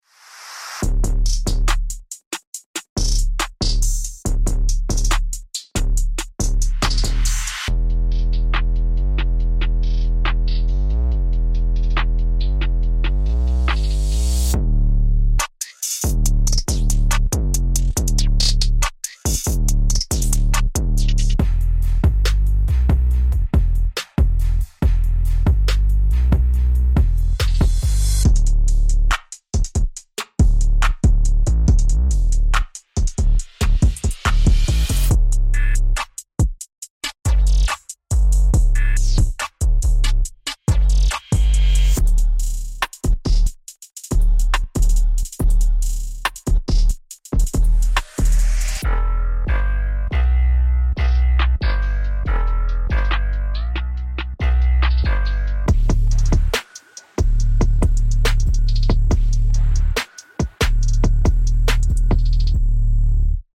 Packed with 100 playable 808 presets, this collection delivers cone-rattling subs, saturated glides, and aggressive, distorted one-shots. Glide-ready presets with perfect pitch tracking. All tuned to C for ease of workflow.